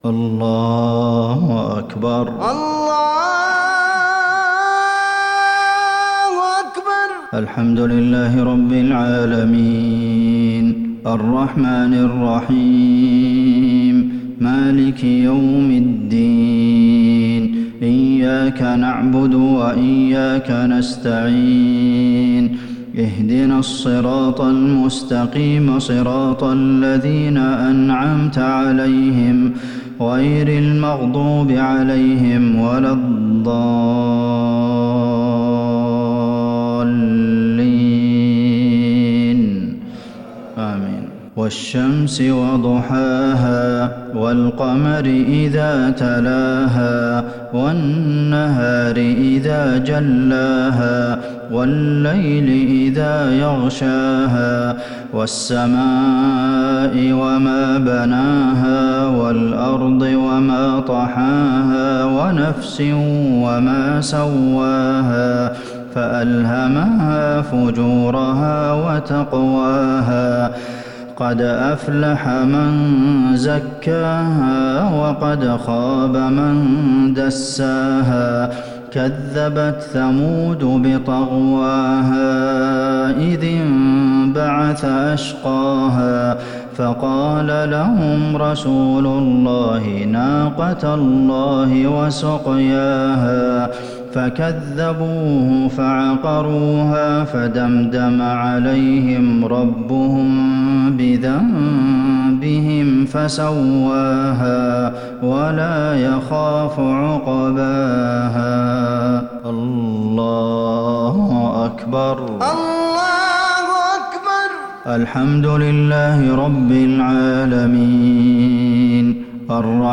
صلاة المغرب للشيخ عبدالمحسن القاسم 6 شوال 1441 هـ
تِلَاوَات الْحَرَمَيْن .